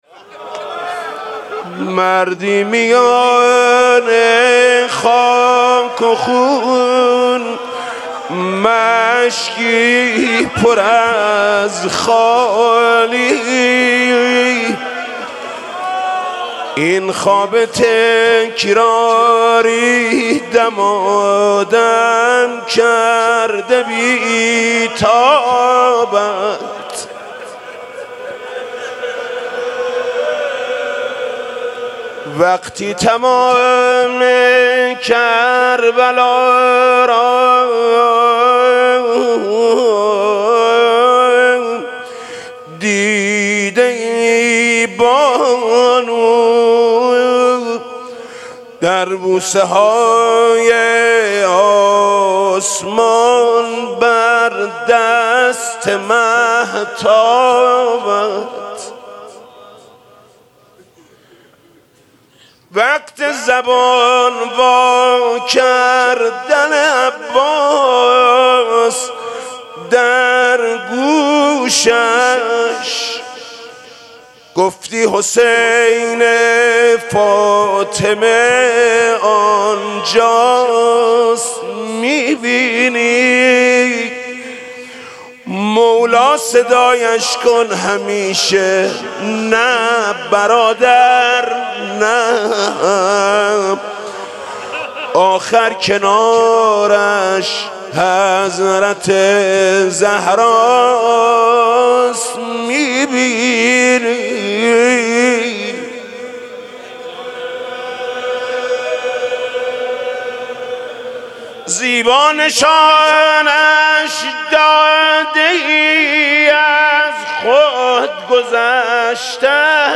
مناسبت : وفات حضرت ام‌البنین سلام‌الله‌علیها
مداح : محمود کریمی قالب : روضه